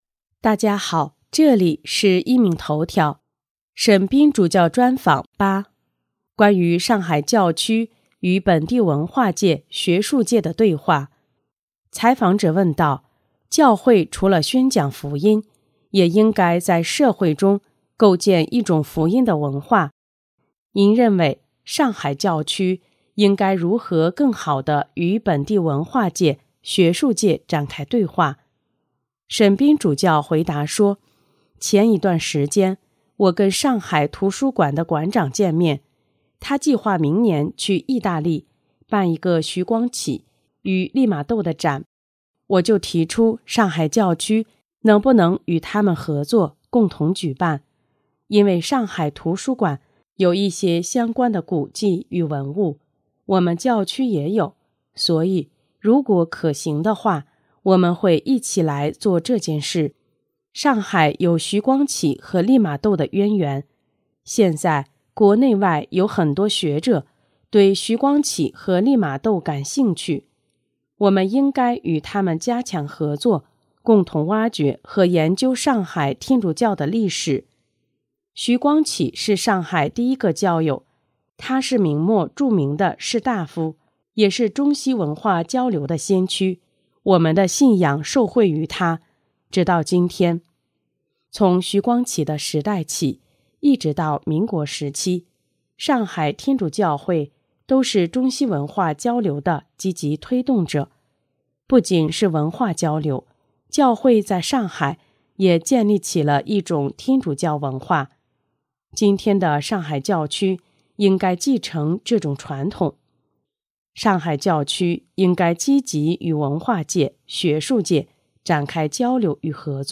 【壹明头条】|沈斌主教专访(八)：关于上海教区与本地文化界、学术界的对话